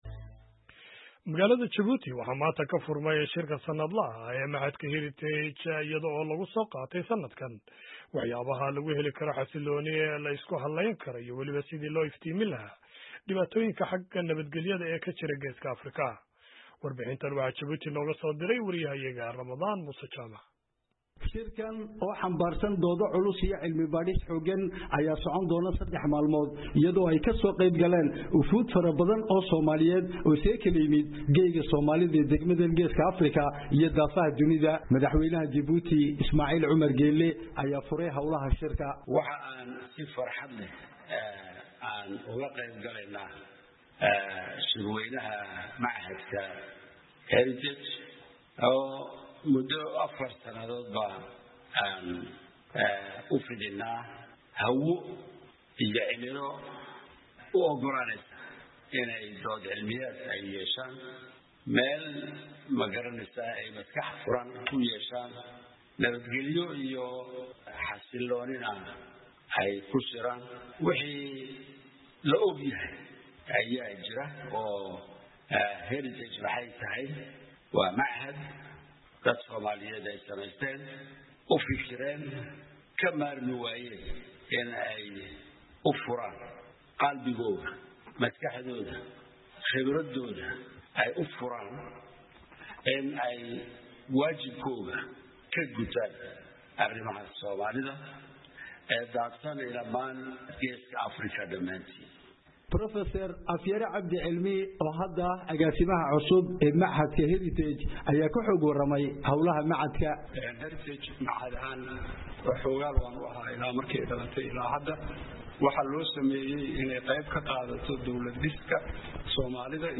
DJIBOUTI —